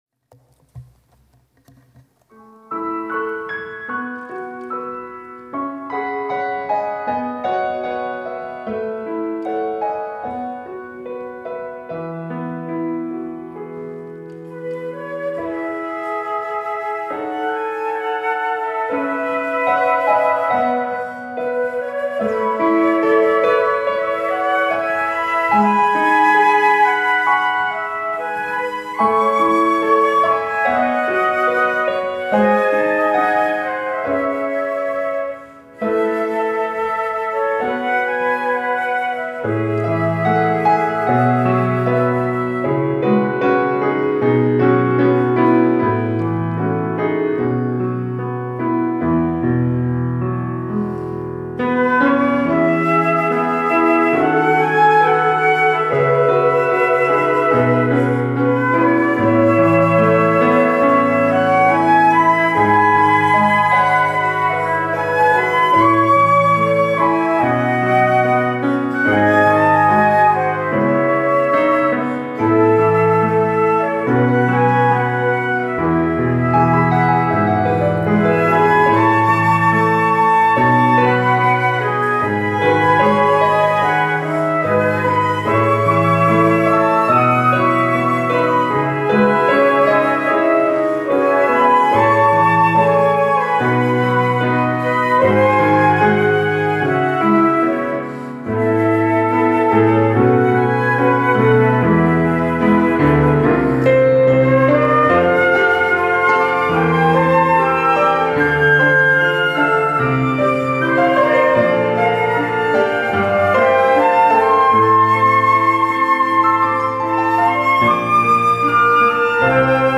특송과 특주 - 오직 주의 사랑에 매여